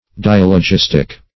Search Result for " dialogistic" : The Collaborative International Dictionary of English v.0.48: Dialogistic \Di*al`o*gis"tic\, Dialogistical \Di*al`o*gis"tic*al\, a. [Gr. ?.] Pertaining to a dialogue; having the form or nature of a dialogue.
dialogistic.mp3